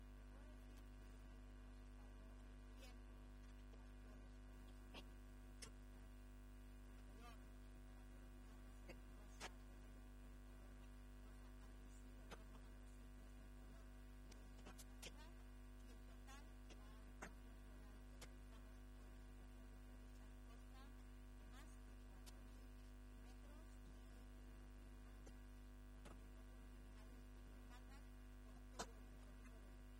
AUDIOS. Milagros Tolón, alcaldesa de Toledo
milagros-tolon_corpus_2_decoracion-floral.mp3